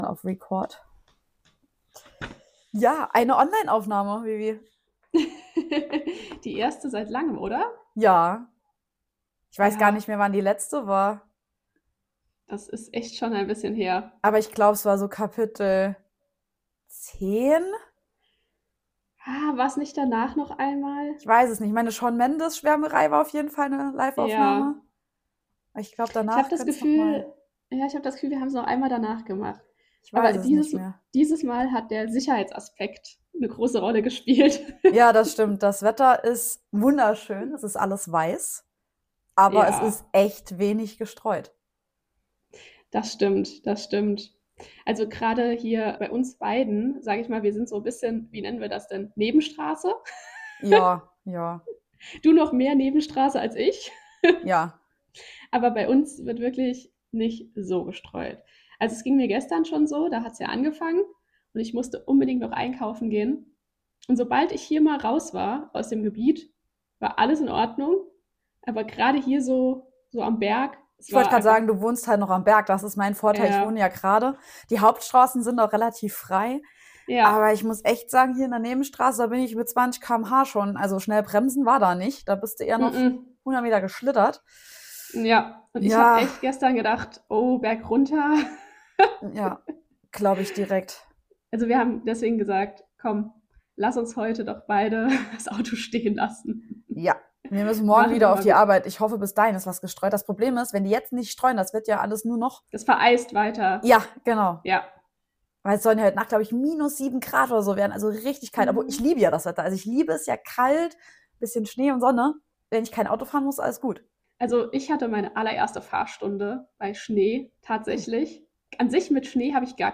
Kapitel 26 bringt das Ferngespräch zurück, sprich die Online-Aufnahme. Das Wetter hat entschieden, uns räumlich voneinander zu trennen, wir lassen uns davon natürlich nicht aufhalten und reden munter weiter.